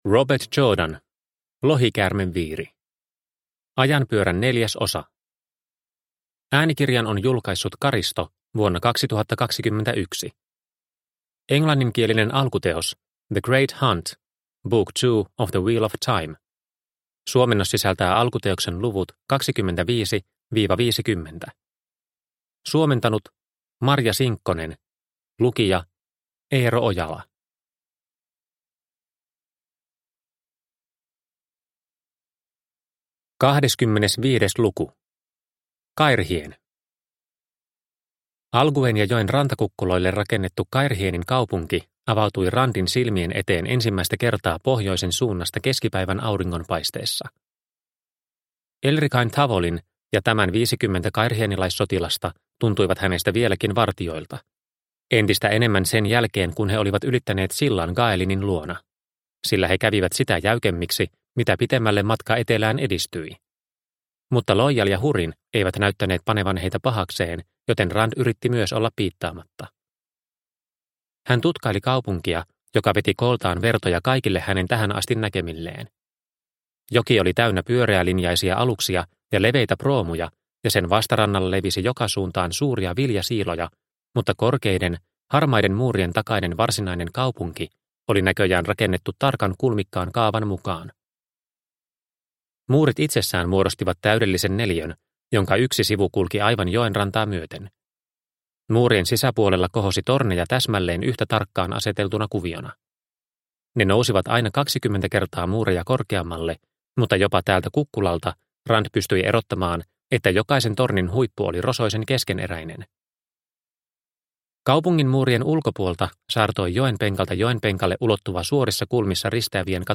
Lohikäärmeviiri – Ljudbok – Laddas ner